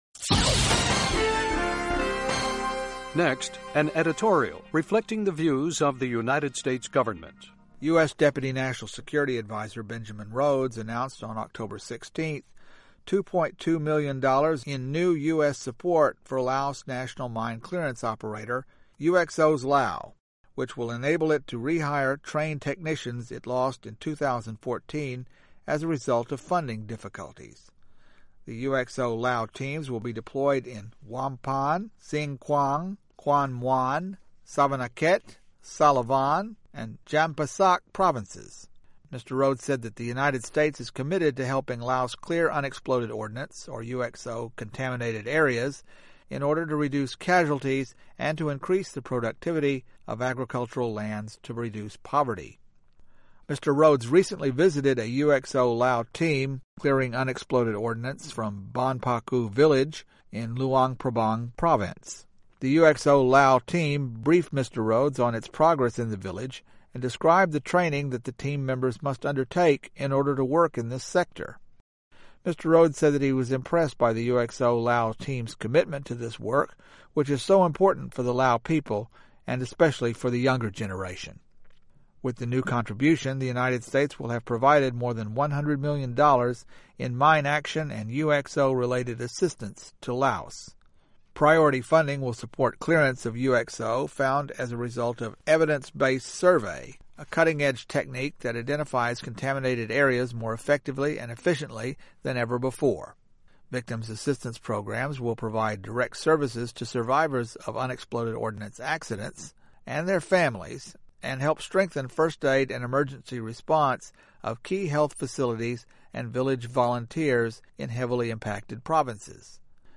Radio Editorials